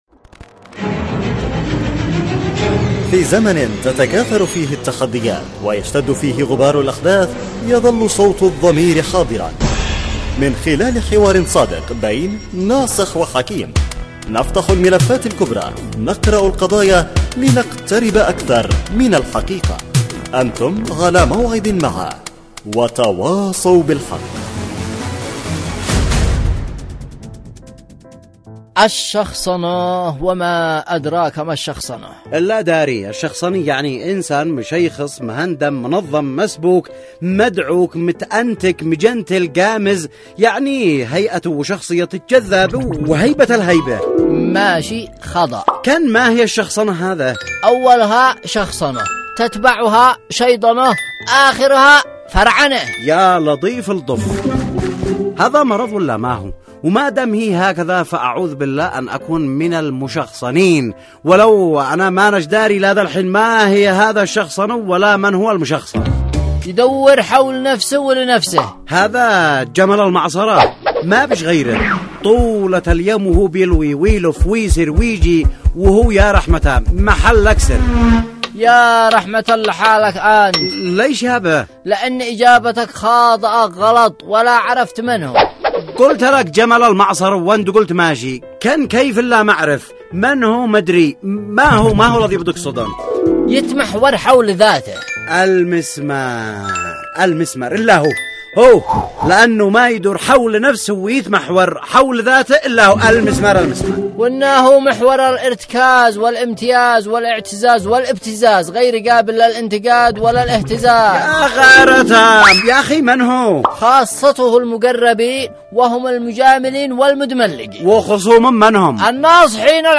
وتواصوا بالحق، برنامج إذاعي درامي يعمل كل يوم على طرح إشكالية و مناقشة مشكلة تهم الجميع وبعد جدال بين الطرفين يتم الاحتكام بينهم الى العودة الى مقطوعة للسيد القائد تعالج المشكلة